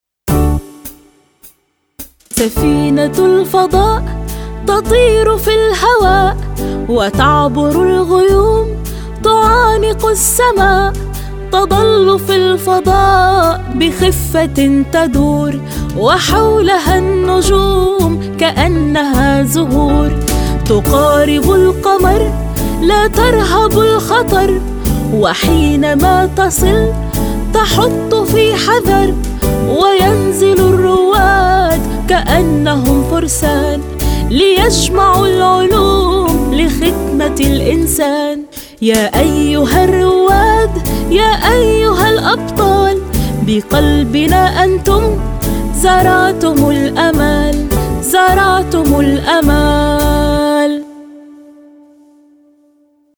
نشيد سفينة الفضاء - لغتنا الجميله - للصف الرابع الاساسي , الفصل الاول
اناشيد نغني